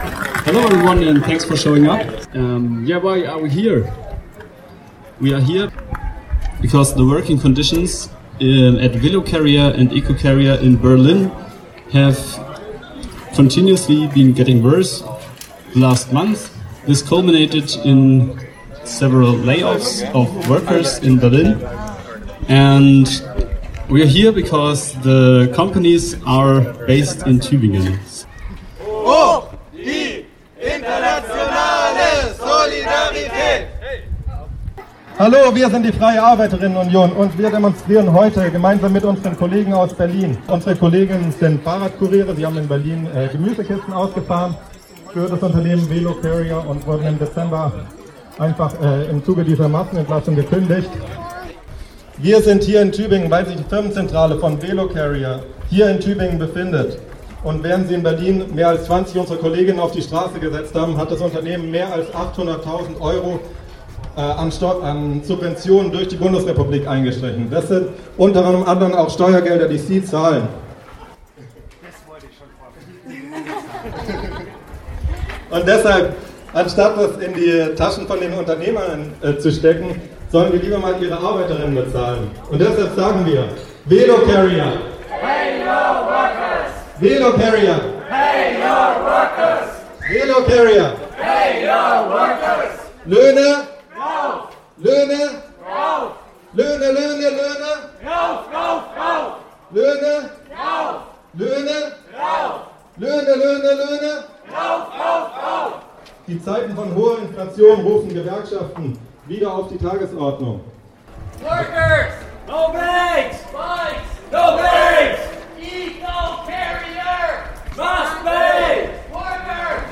In dem Audiobeitrag unten sind Stimmen der Demonstration und Kundgebung am 17.6. in Tübingen zu hören, die die Hintergründe des Protests erläutern.
Demo-Mitschnitt: Arbeitskampf gegen Velocarrier am 17.6.2023 in Tübingen